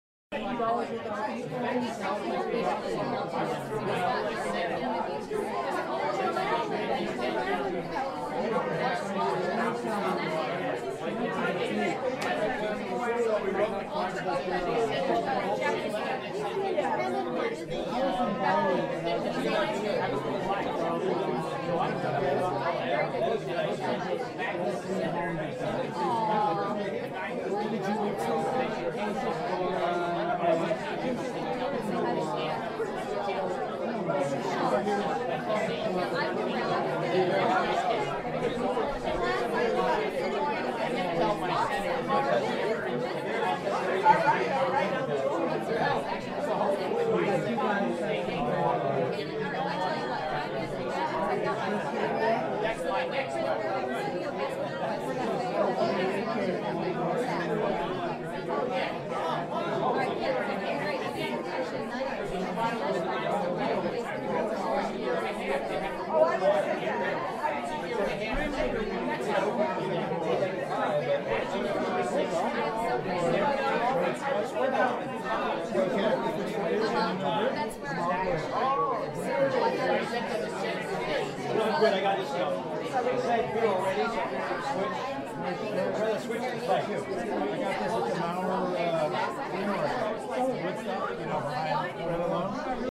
Звук беседы людей в комнате